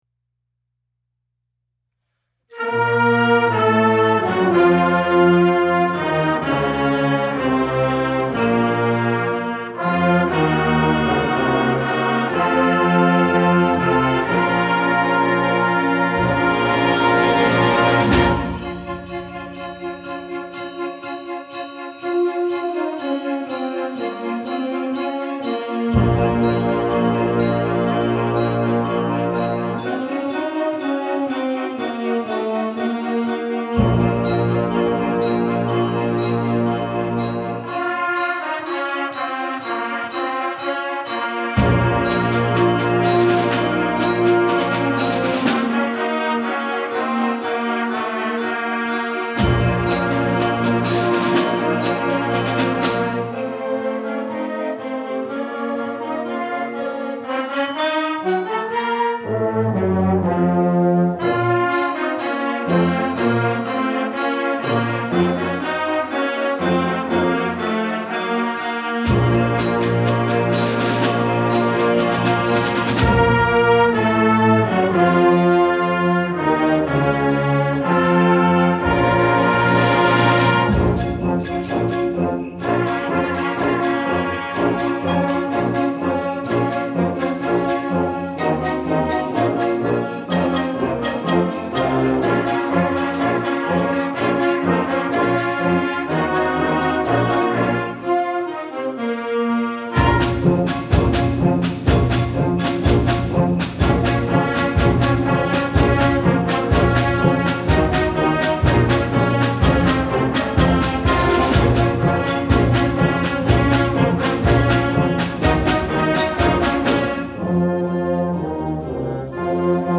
Winter Concert
--CONCERT BAND 2--